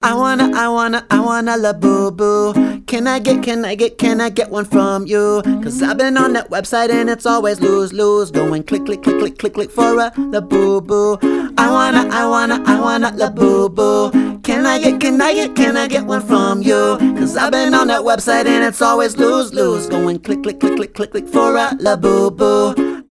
поп
гитара